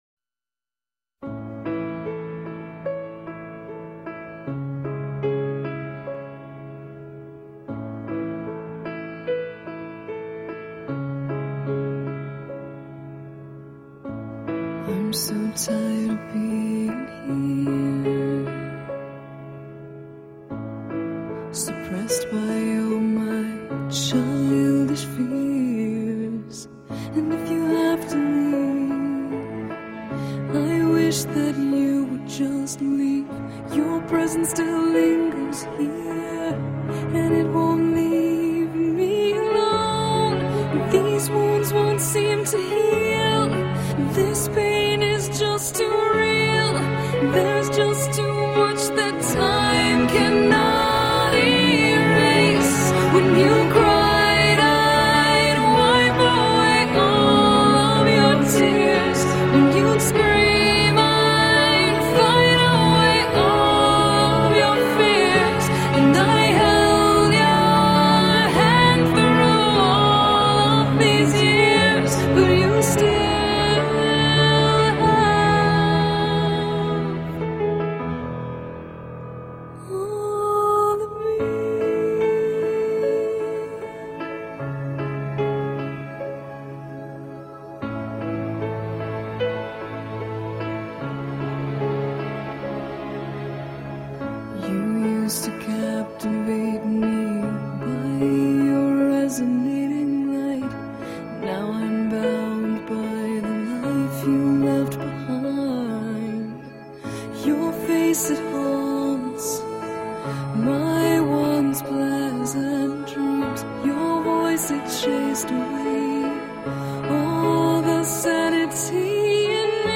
این اهنگ خیلی باحاله انگار دور سرت داره میچرخه حتما با هنزفری گوش کنید